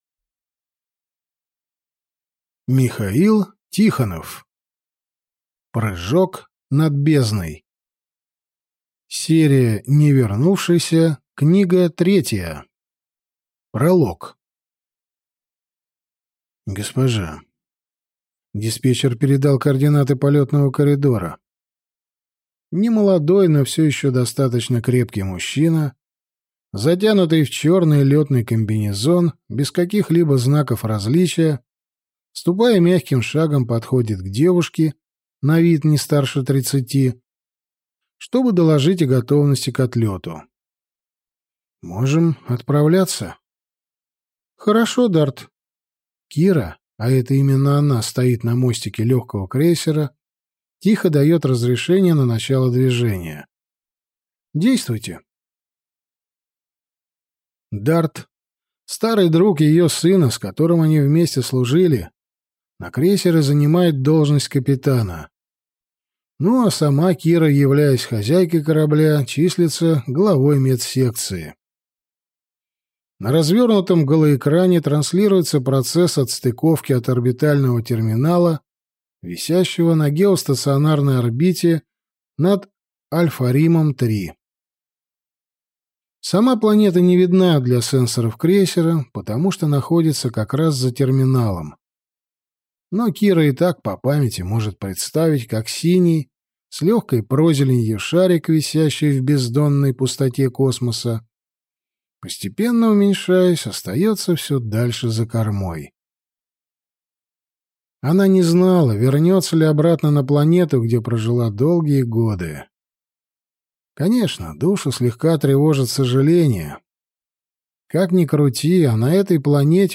Аудиокнига Прыжок над бездной | Библиотека аудиокниг